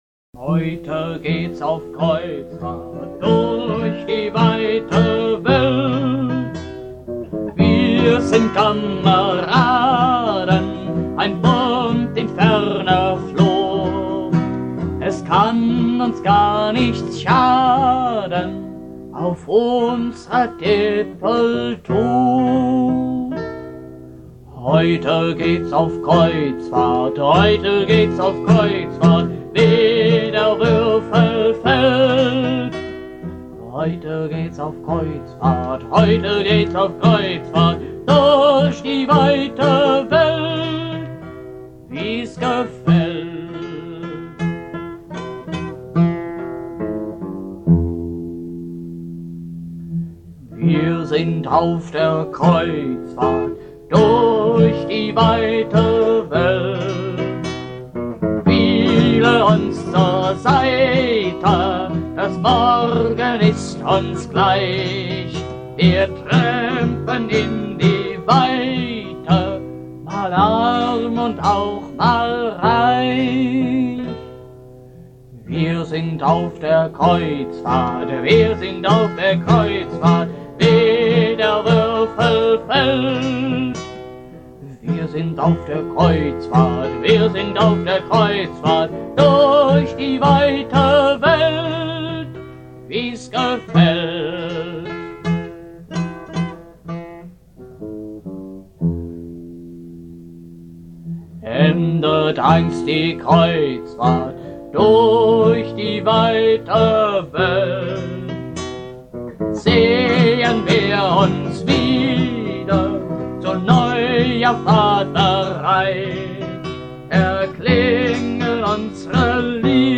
Meine frühen Lieder